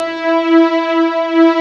BIGPADE5.wav